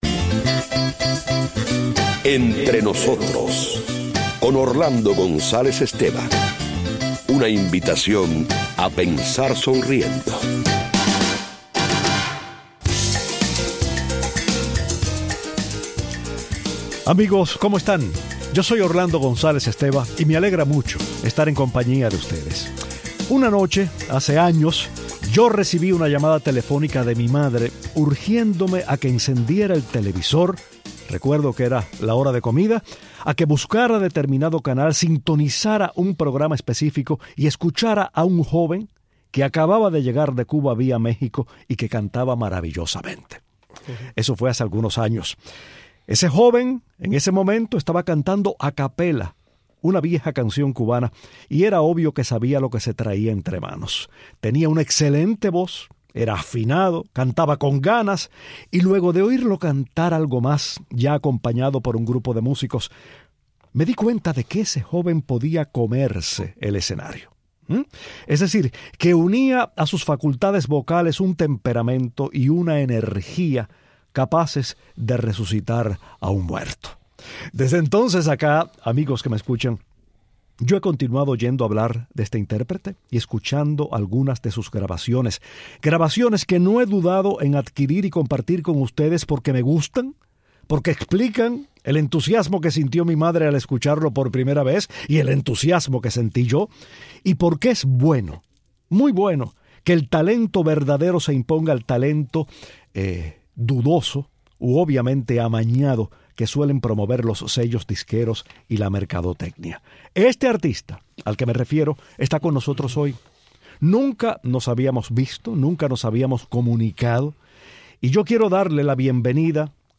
El destacado cantante cubano habla de su relación con la música como intérprete y compositor, de sus planes, del rol que ha jugado la música bailable en su carrera y de su pasión por la balada, donde una voz como la suya puede hacer derroche de extensión y expresividad.